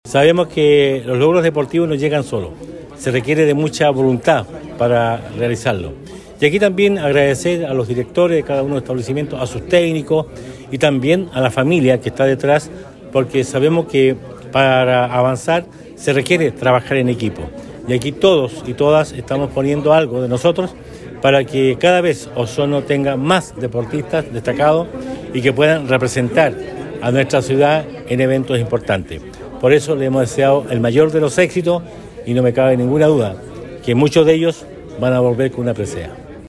Estos logros deportivos vienen de la mano del apoyo constante de las familias, equipos técnicos y establecimientos educacionales, por lo que el jefe comunal recalcó que se continuará apoyando a los deportistas locales.